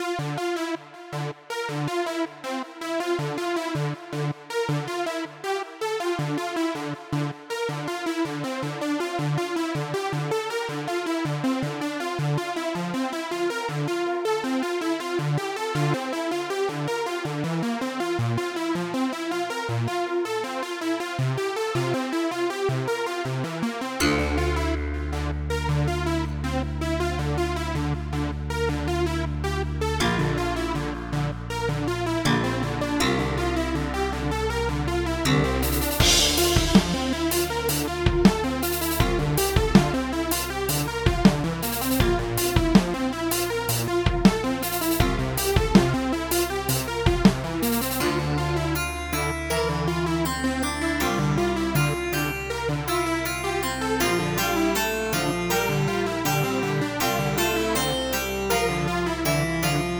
revelationsynth_0.ogg